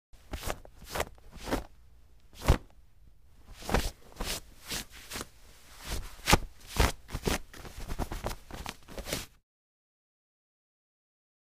Cloth, Wipe
Brushing Off Cotton Shirt With Hands